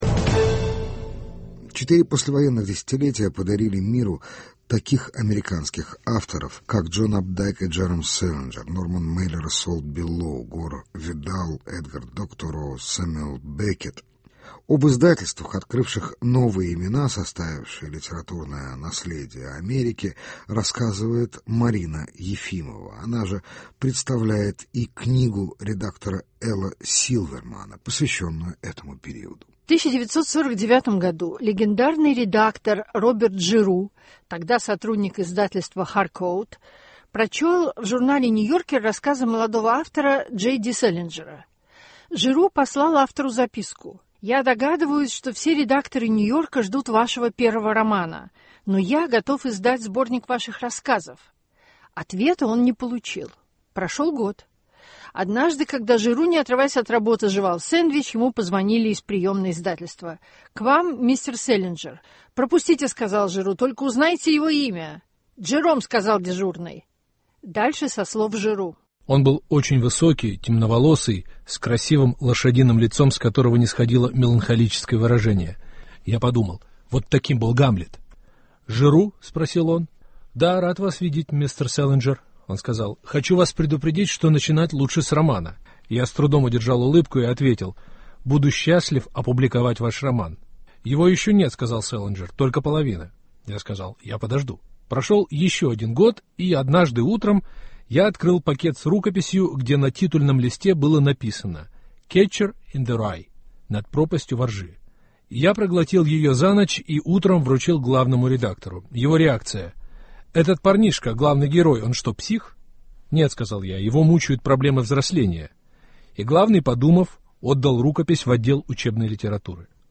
Литература Америки середины прошлого века: интервью с автором книги.